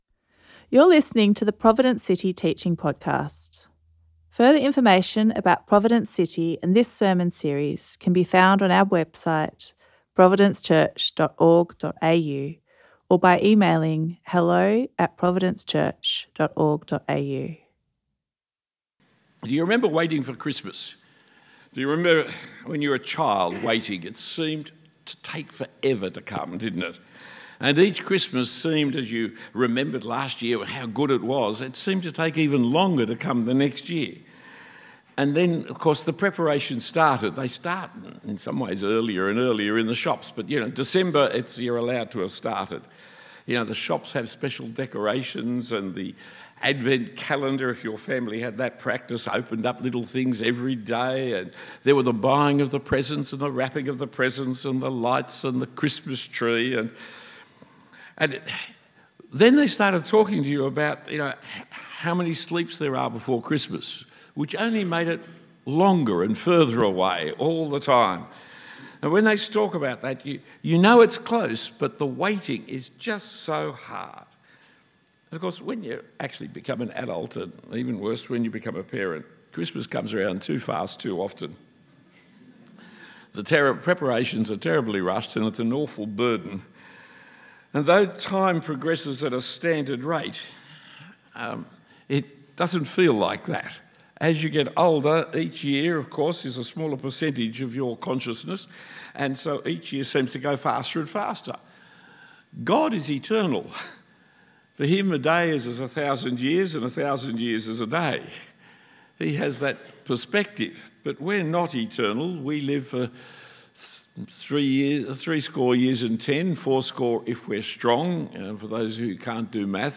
A sermon given at Providence City Church, Perth.